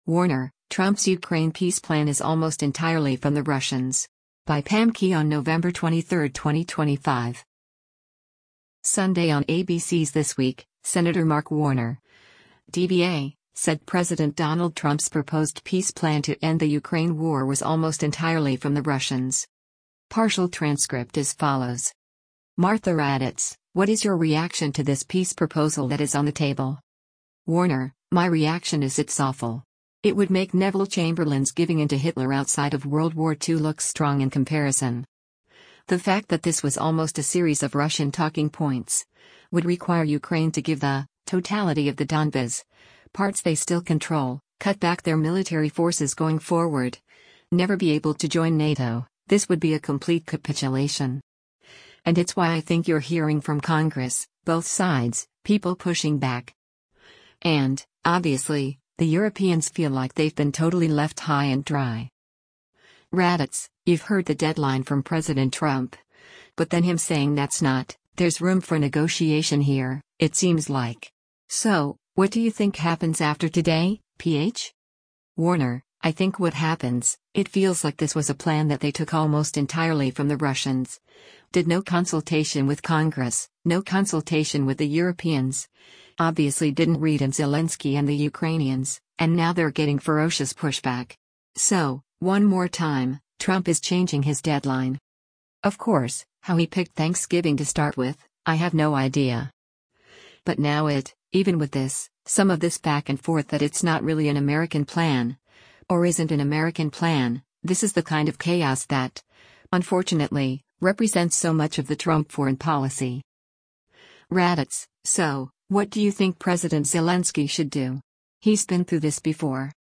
Sunday on ABC’s “This Week,” Sen. Mark Warner (D-VA) said President Donald Trump’s proposed peace plan to end the Ukraine war was “almost entirely from the Russians.”